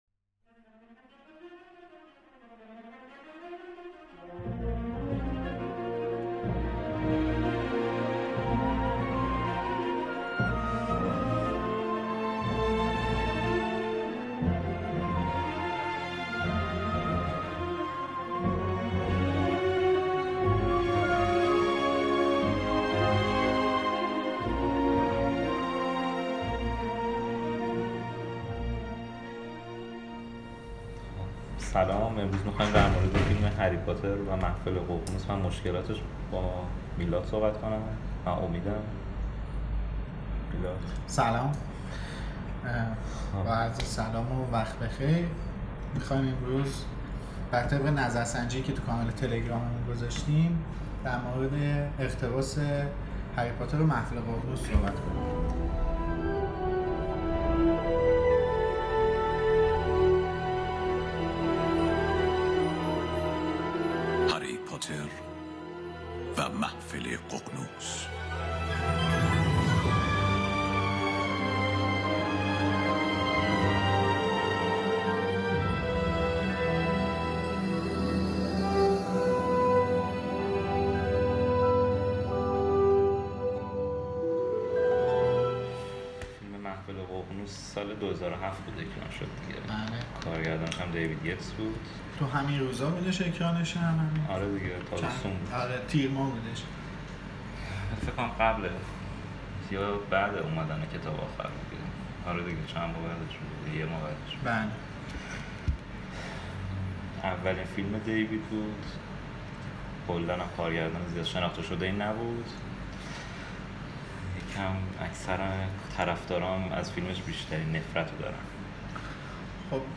در اولین قسمت از دوره‌ی جدید مجموعه پادکست‌های دمنتور، نگاهی داریم به اقتباس نسخه‌ی سینمایی فیلم «هری پاتر و محفل ققنوس»، اثر دیوید یتس که اولین کارگردانی او در دنیای جادوگری جی.کی.رولینگ به حساب می‌آید. این قسمت به عنوان اپیزودی آزمایشی جهت دریافت بازخوردهای شما تهیه شده و قسمت‌های بعدی قطعا با کیفیت محتوایی و صوتی بالاتری تولید خواهد شد و امیدوارم ضعف‌های احتمالی ما را ببخشید.